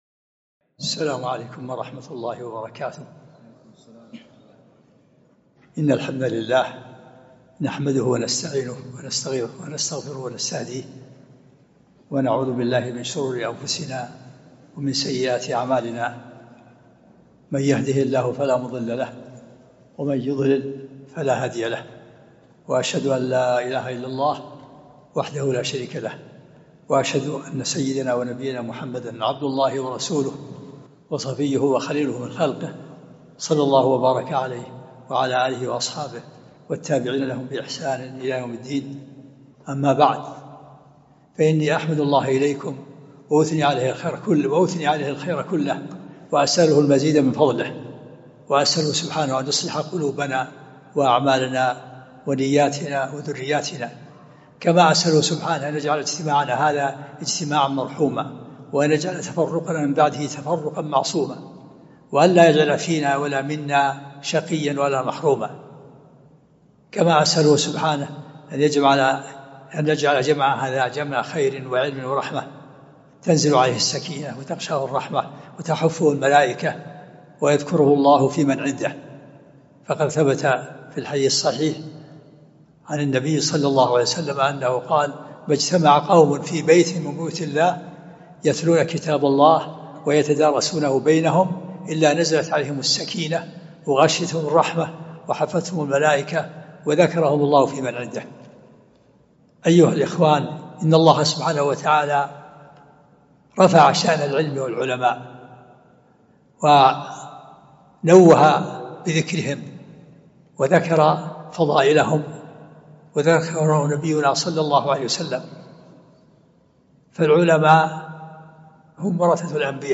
محاضرة فضل العلم ومكانة العلماء
محاضرة (فضل العلم ومكانة العلماء.mp3